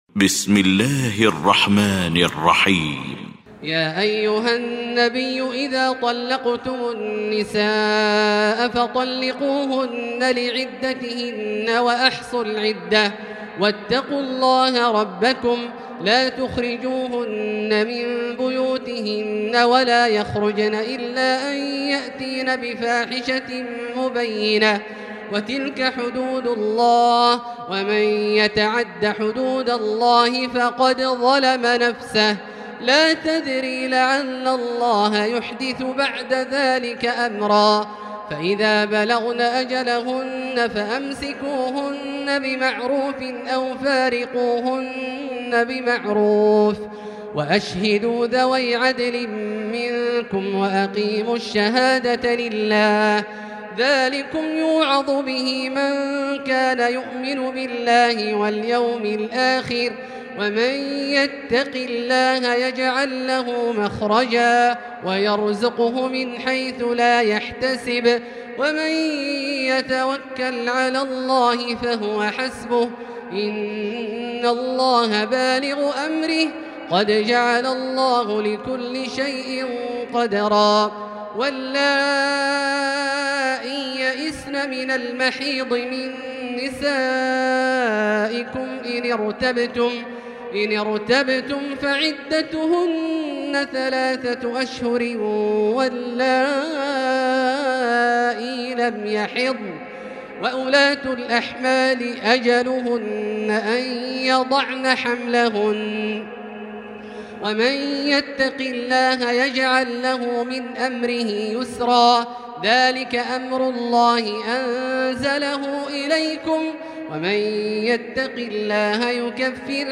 المكان: المسجد الحرام الشيخ: فضيلة الشيخ عبدالله الجهني فضيلة الشيخ عبدالله الجهني الطلاق